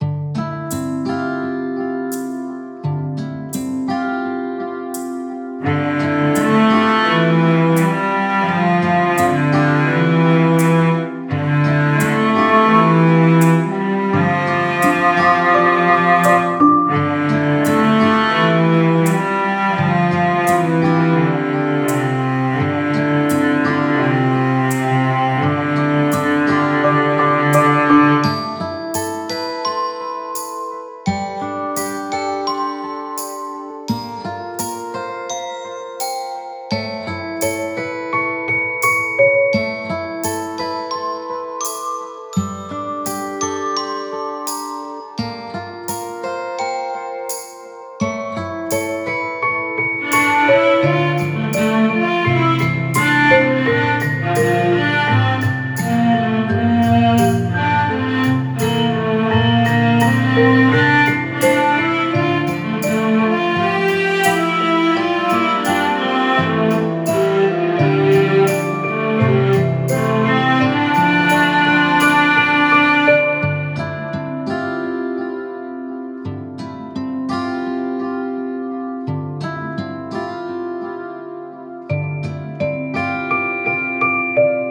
• テンポ：ゆったりとしたミドル〜スロー
• 構成：大きな展開を作らず、反復を重視
• 音域：低〜中域中心で、耳に刺さらない設計
リバーブは広がりすぎないよう抑え、「開けた山」ではなく閉ざされた山奥の空間感を意識しています。
fukaiyamaokuLOOPOGG
フリーBGM ホラー アンビエント 切ない 癒し ピアノ ミステリー 自然 回想